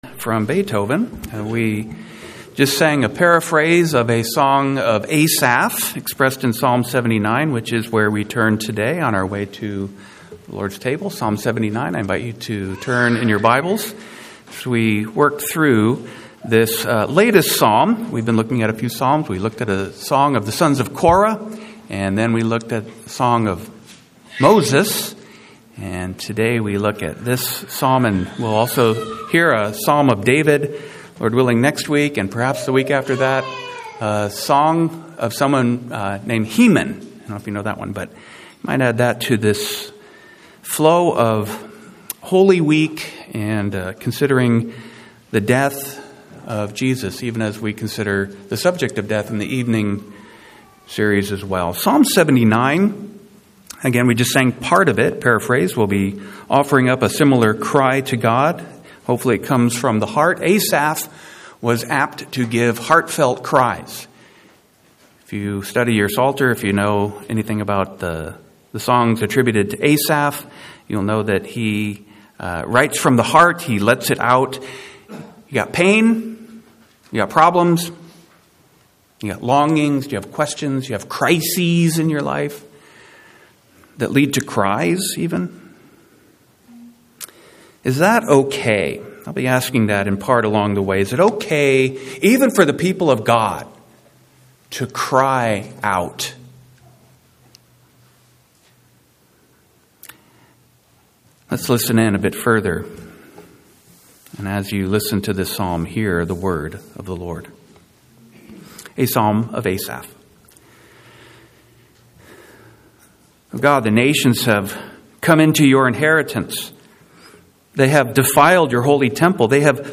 Occasional Sermons